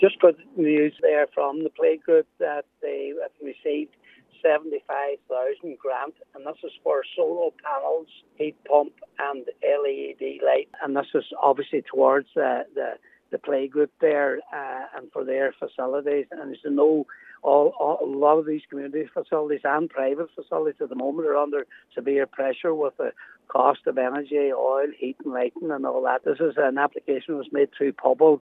Cathaoirleach of the Lifford-Stranorlar Municipal District, Cllr. Patrick McGowan has welcomed the grant, saying many facilities are struggling with increasing prices: